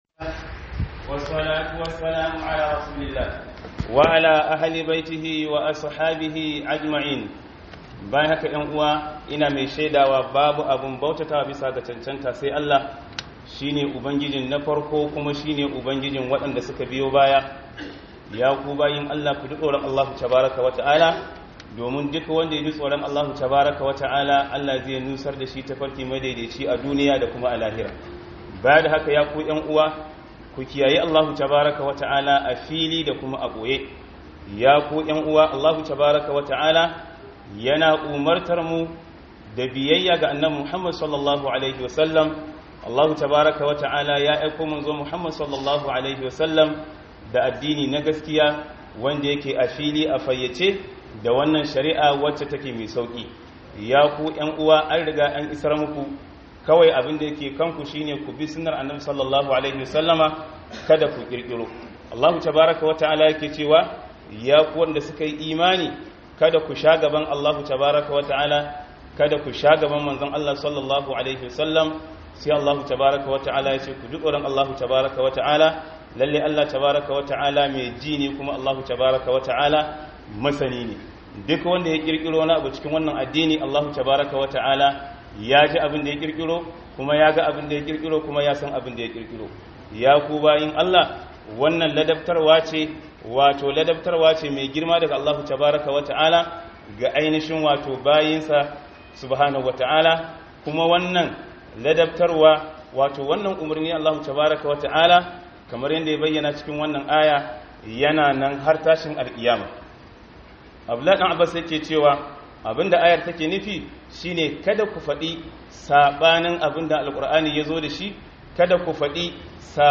Khudubar Jibwis NEPA Ningi - Son zuciya